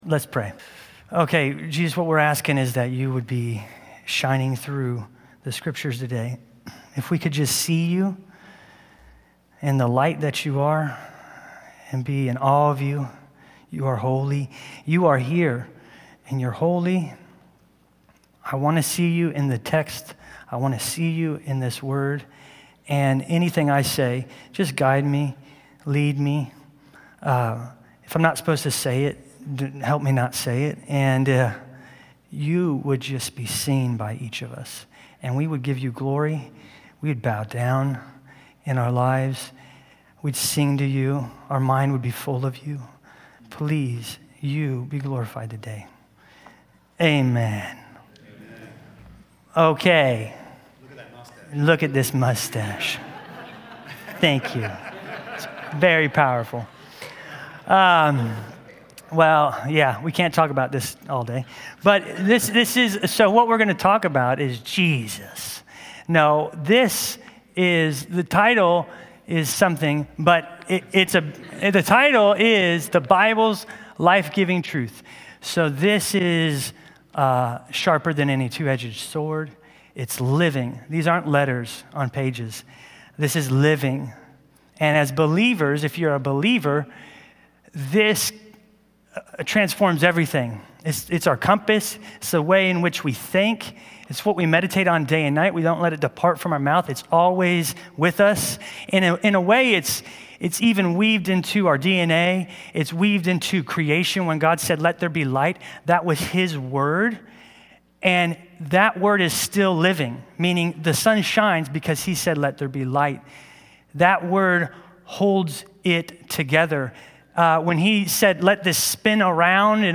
A message from the series "Bible Revival."